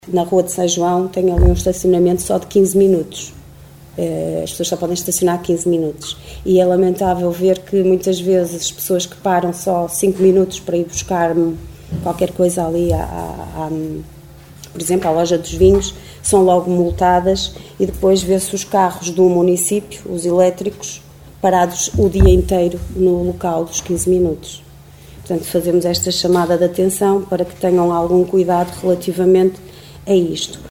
O reparo foi feito na última reunião do executivo pela vereadora Liliana Silva da OCP, que se referia às zonas de estacionamento na rua de São João onde só é permitido estacionar durante 15 minutos.
Liliana Silva a pedir à Câmara que dê o exemplo e não estacione as suas viaturas indevidamente.